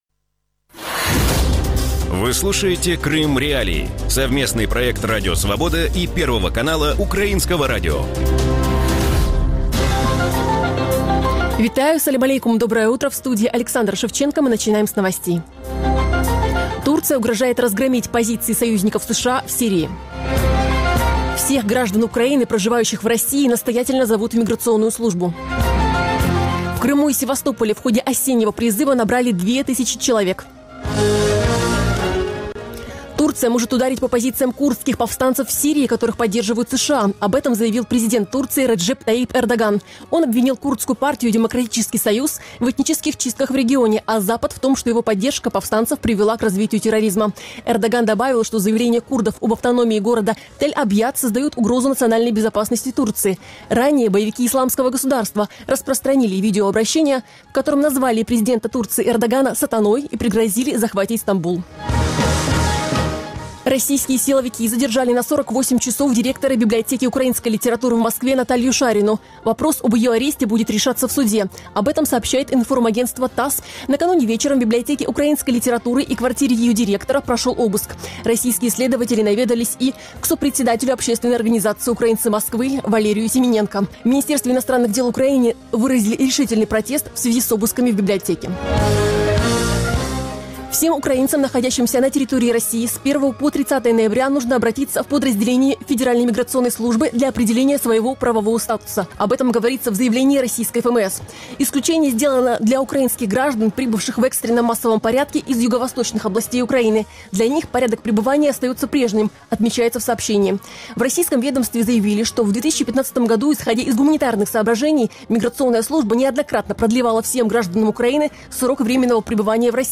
Как изменился русский язык с момента аннексии Крыма и украино-российского конфликта? Об этом в эфире Радио Крым.Реалии